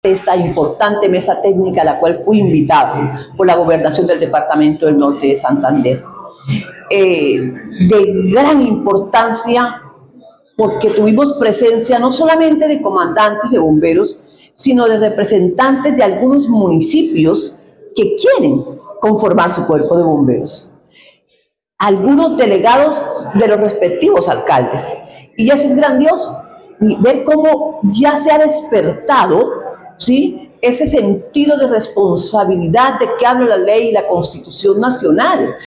2. Audio de Lourdes Peña, directora nacional de bomberos
Audio-de-Lourdes-Pena-directora-nacional-de-bomberos.mp3